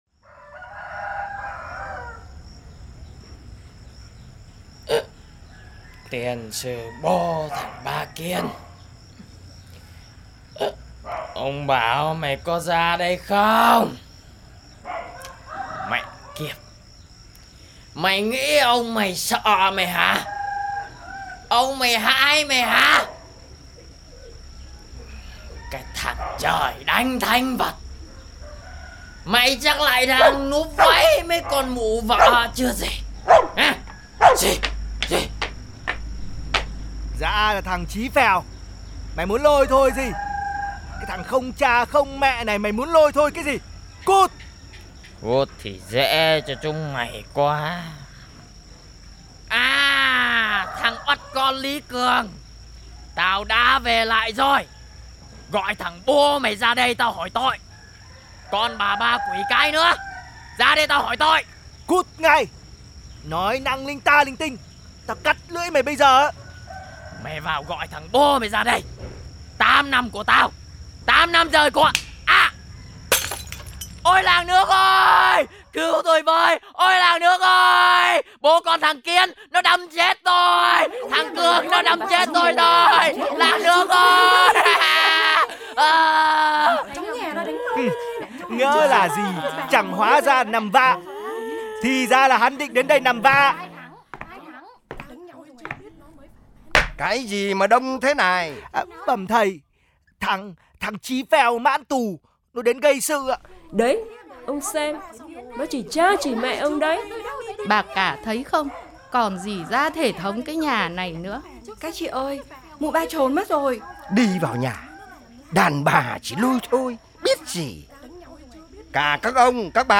Kịch Truyền Thanh By LKLK - Loại hình nghệ thuật biểu diễn sân khấu
Tiếng động
Lời thoại
Âm nhạc
Hãy đeo tai nghe, nhắm mắt lại và để chúng tôi dẫn bạn vào câu chuyện của riêng mình.
canh-2-demo-website.mp3